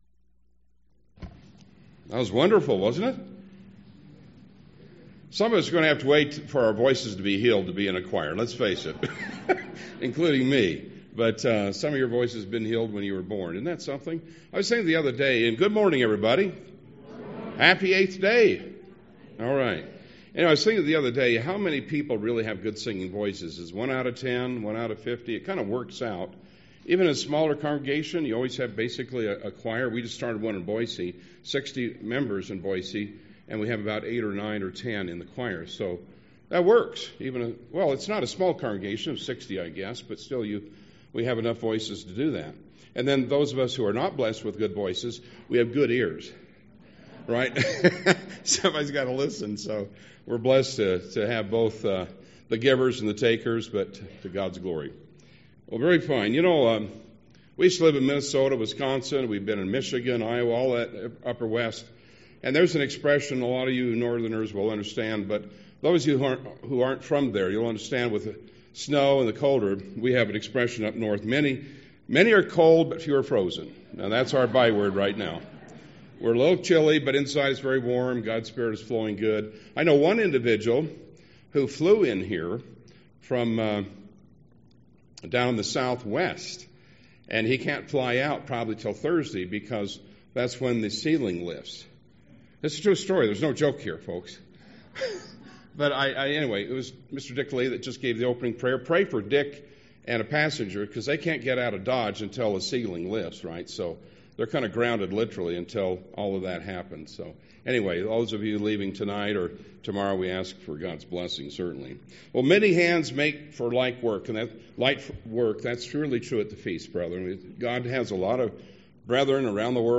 This sermon was given at the Steamboat Springs, Colorado 2019 Feast site.